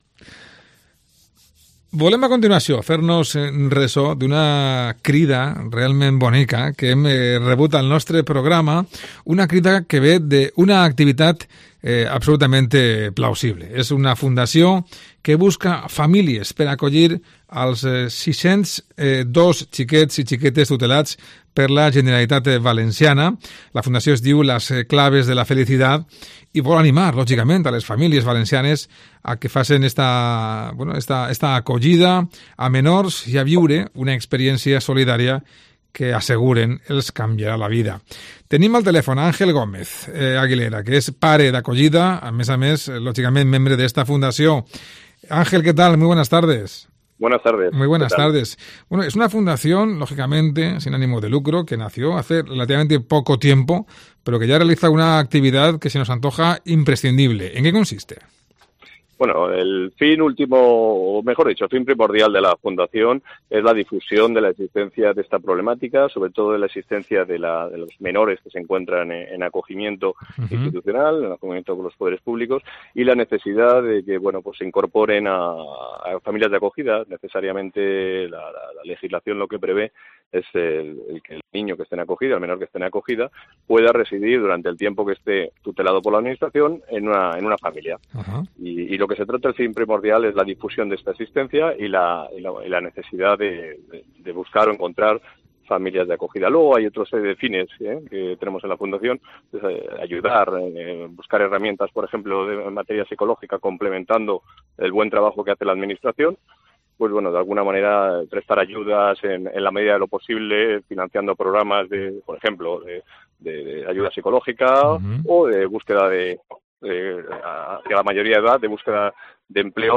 Entrevista a la fundación Las Clavel de la Felicidad